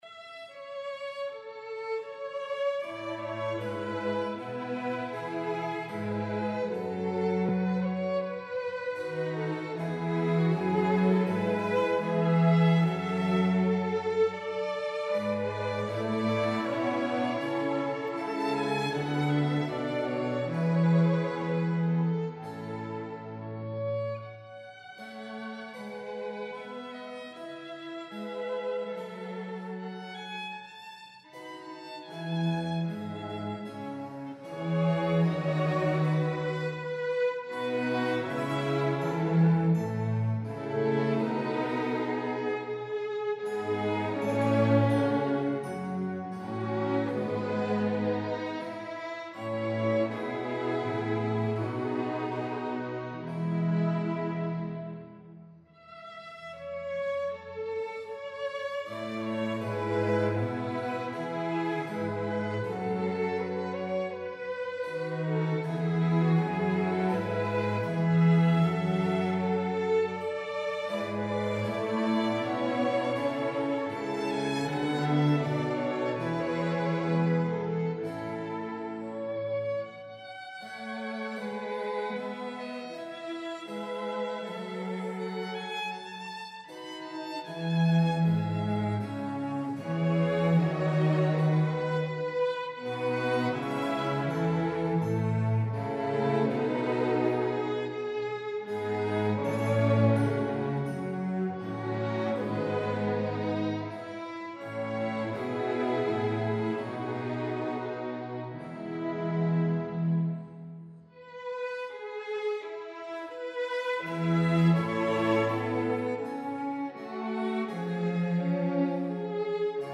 in A Major